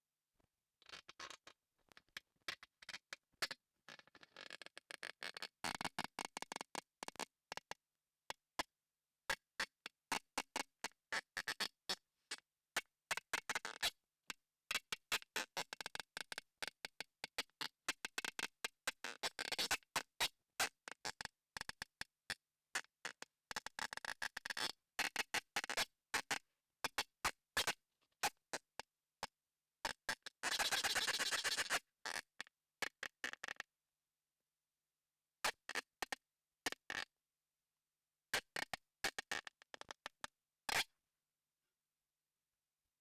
scrr_cymbal1.wav